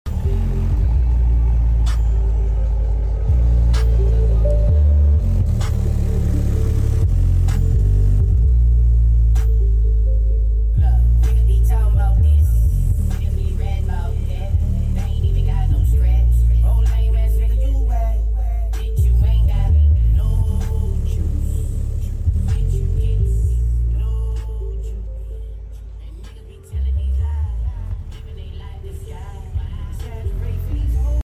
Custom Triple 8” enclosure for sound effects free download